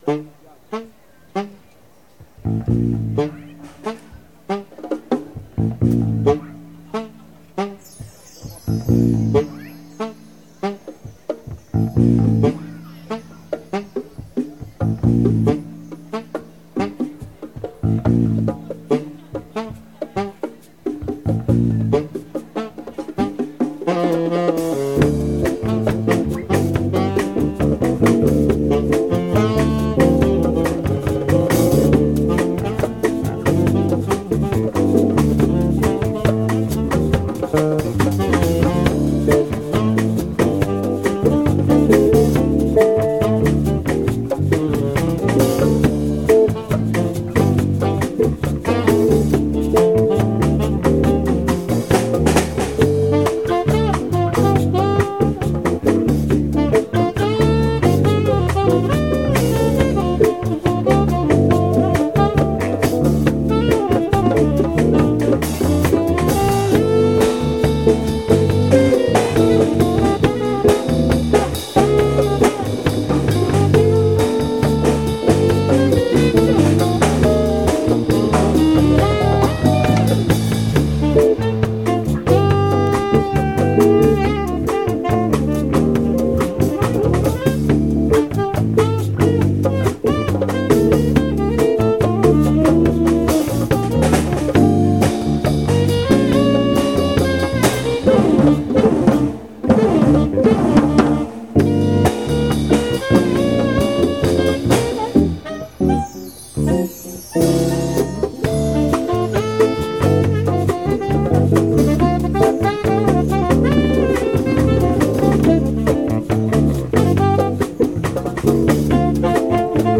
flavta, vokal
kitara
fretless bas
tolkala
bobni
bogata zmes glasbenih zvrsti